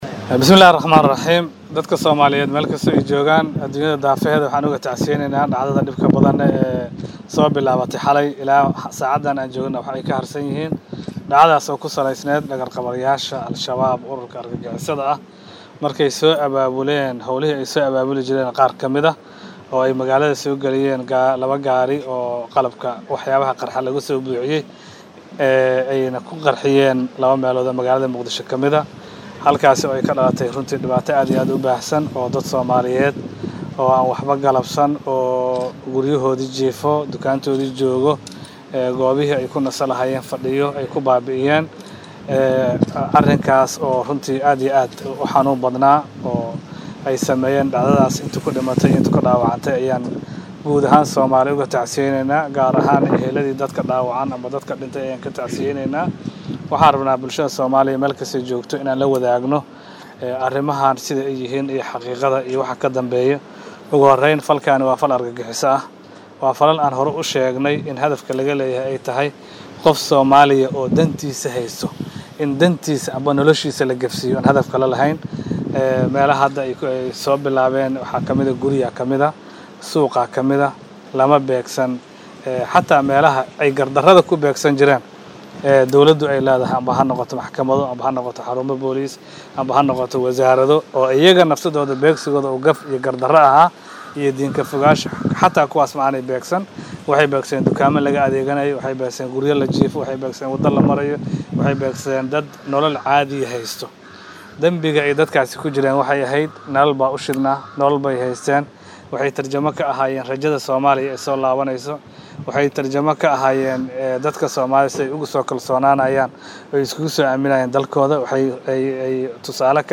Wasiirka Warfaafinta, Dhaqanka iyo dalxiiska ee Xukuumadda Soomaaliya Mudane Daahir Maxamuud Geelle ayaa shir jaraa'id oo uu ku qabtay Muqdisho
SHIR-JARAAID-WASIIRKA-WARFAAFINTA-XFS.mp3